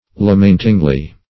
lamentingly - definition of lamentingly - synonyms, pronunciation, spelling from Free Dictionary Search Result for " lamentingly" : The Collaborative International Dictionary of English v.0.48: Lamentingly \La*ment"ing*ly\, adv.